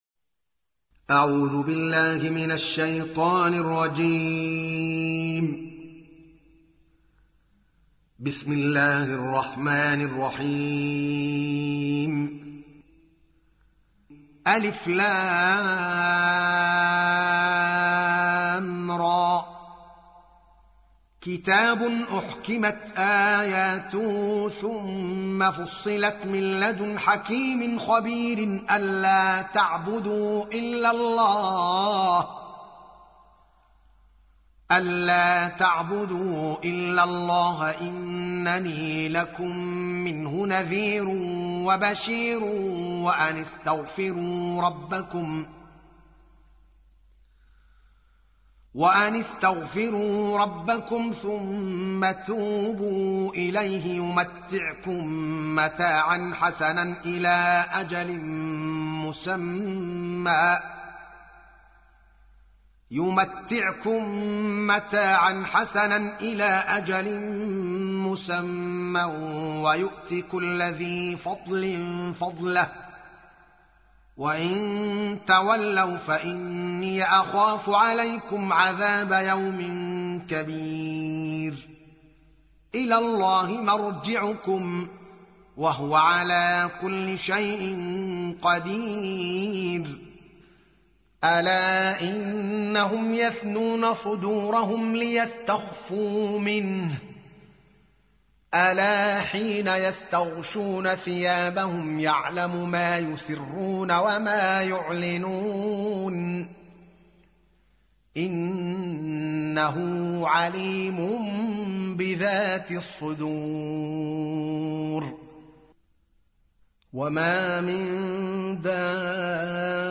تحميل : 11. سورة هود / القارئ الدوكالي محمد العالم / القرآن الكريم / موقع يا حسين